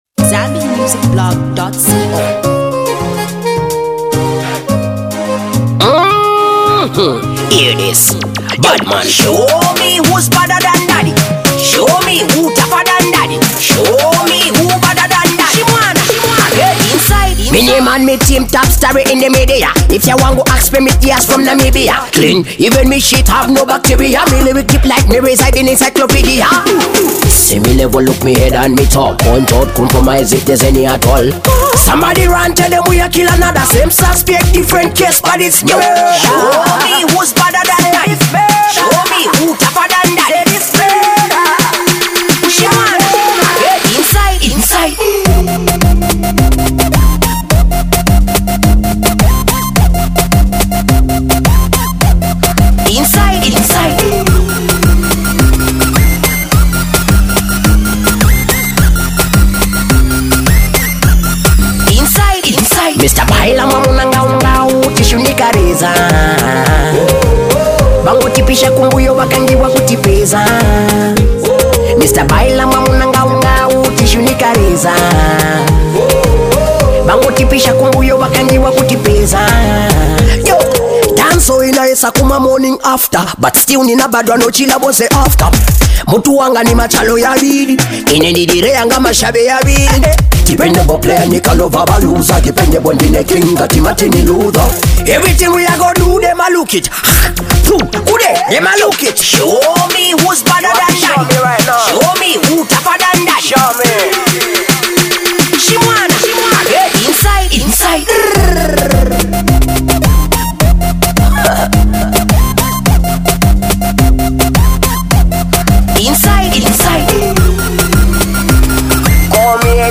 His first Dance-Hall release for 2015.